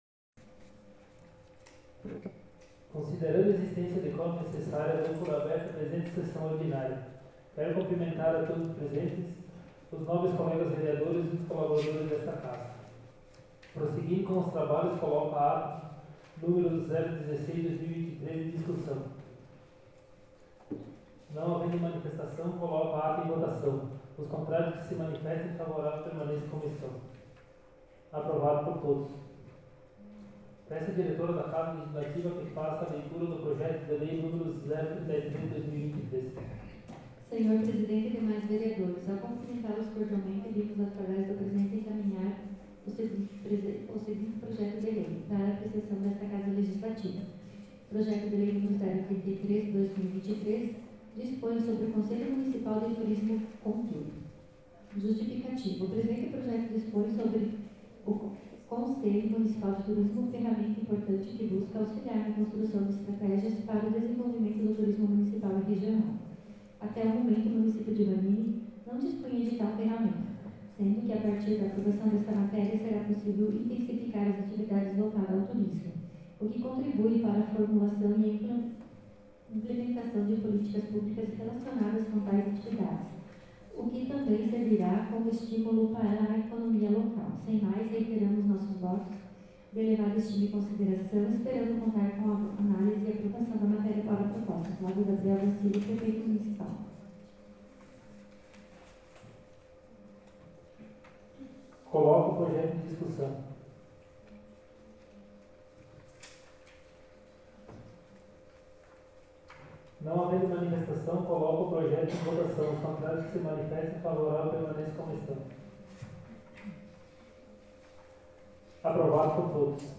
Em anexo arquivo de gravação em áudio da Sessão Ordinária realizada na Câmara de Vereadores de Vanini na data de 10/10/2023.